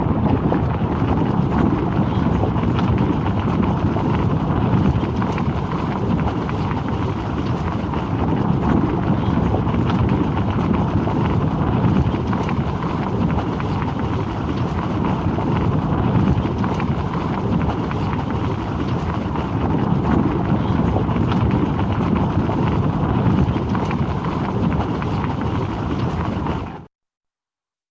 Громкие, ритмичные удары копыт создают эффект присутствия – используйте для звукового оформления, релаксации или творческих проектов.
Тысячи животных мчатся по земле (звук)